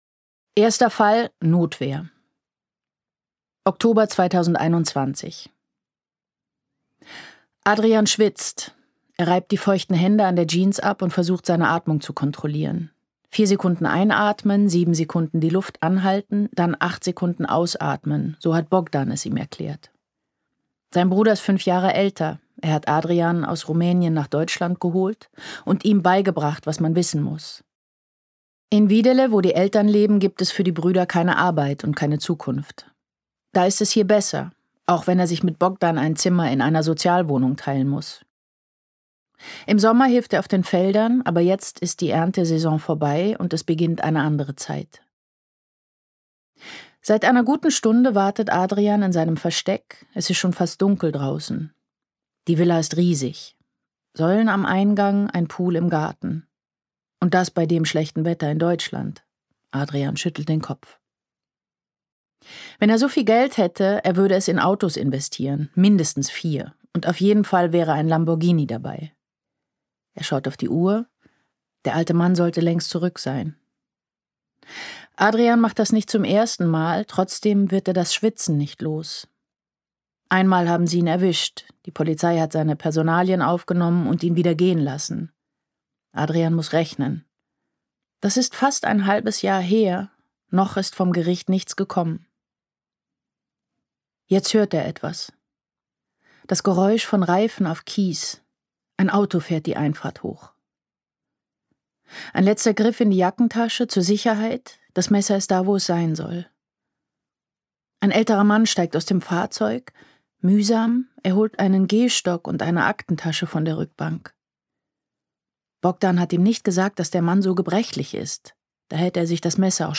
Nina Kunzendorf (Sprecher)